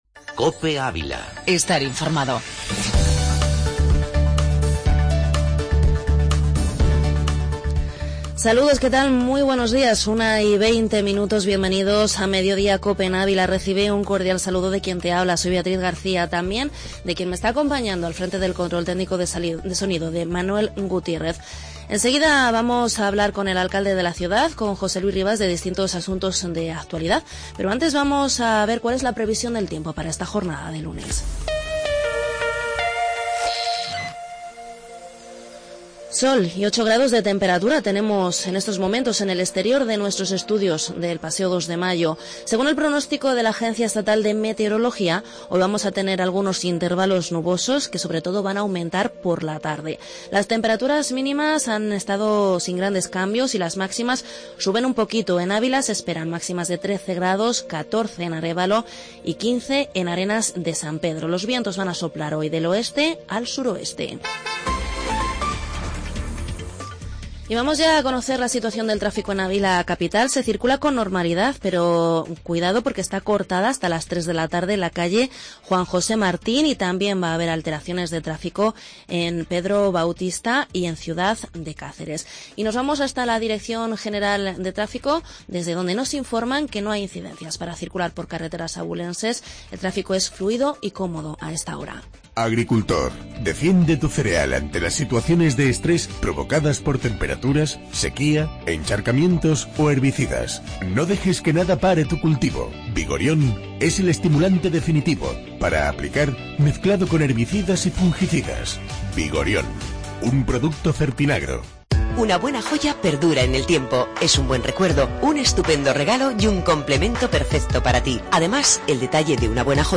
AUDIO: Entrevista Alcalde de Ávila